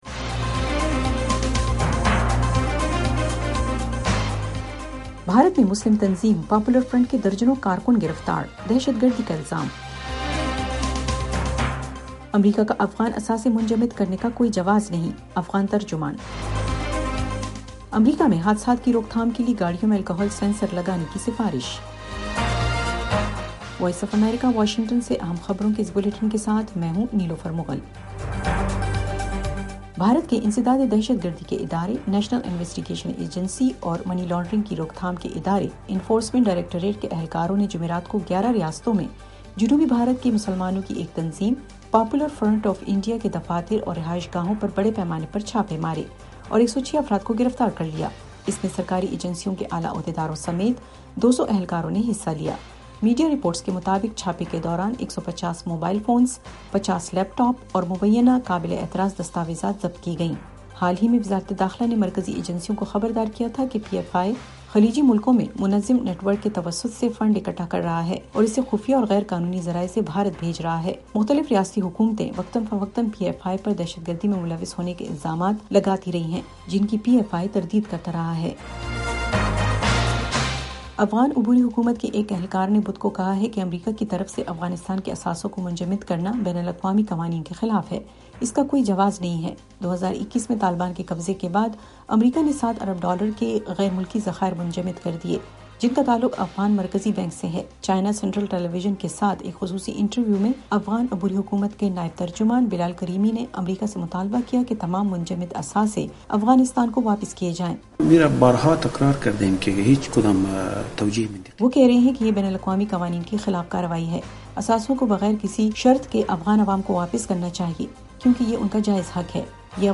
ایف ایم ریڈیو نیوز بلیٹن : رات 10 بجے